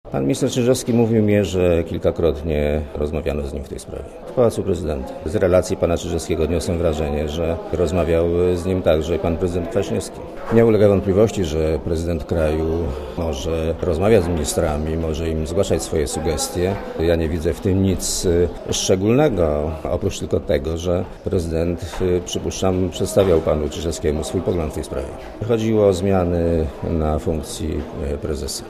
Mówi były premier Leszek Miller